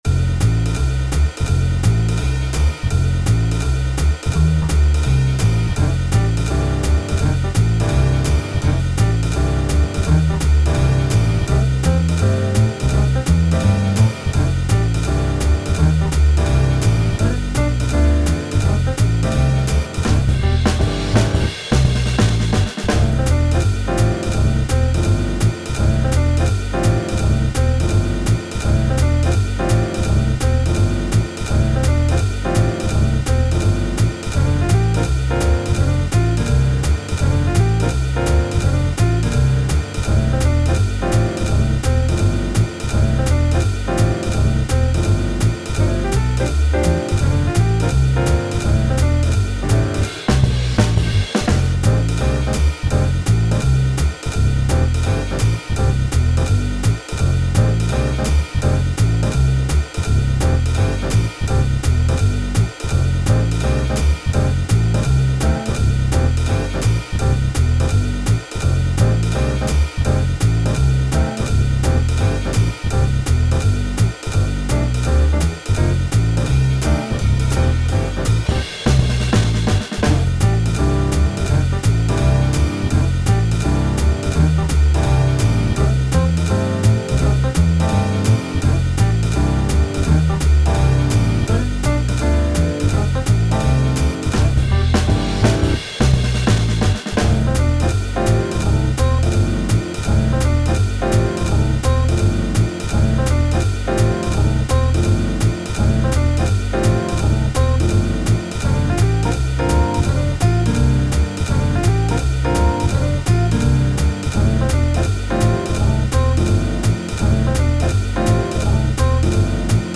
タグ: おしゃれ ゲーム 明るい/楽しい 華やか コメント: 登録者達成企画で作った楽曲。